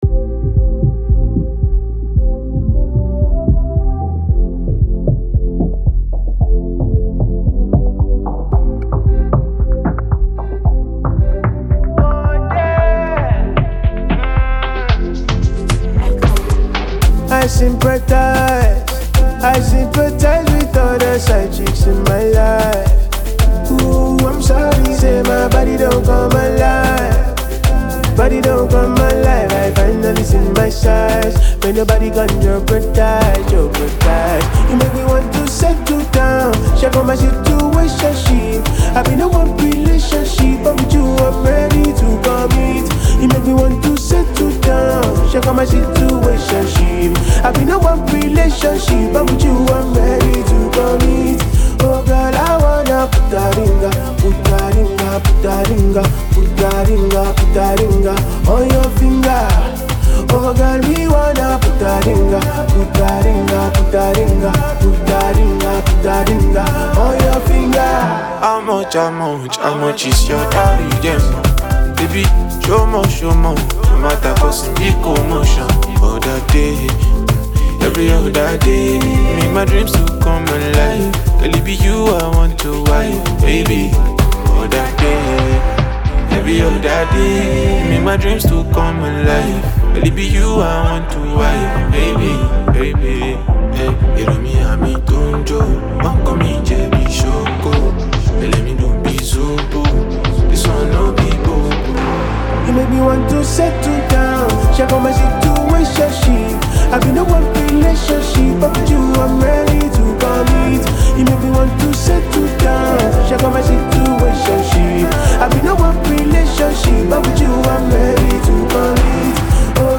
a potential hit that beautifully blends melody and emotion